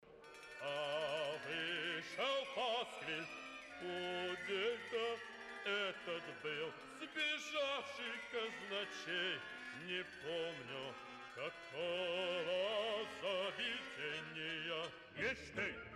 Newspaper office with Flexatone
The Flexatone sounds just funny and strange.